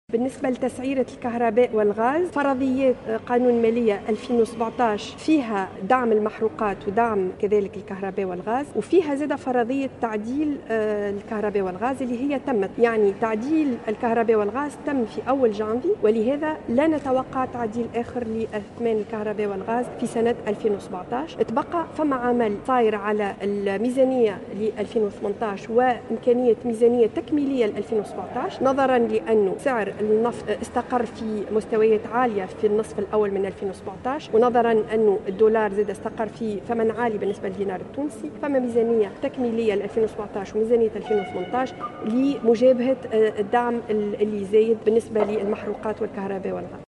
La ministre de l'Energie, des Mines et des Energies renouvelables, Héla Cheikhrouhou, a indiqué vendredi que la loi de finances 2017 ne prévoit pas de hausse des tarifs de l'électricité et du gaz jusqu'à la fin de l'année.